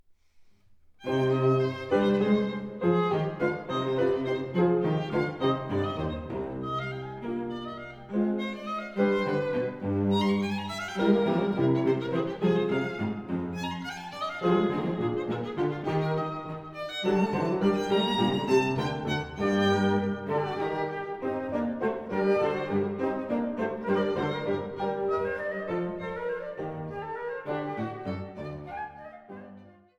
Flöte
Violoncello
Klavier
Das Cembalo wird durch das Klavier ersetzt.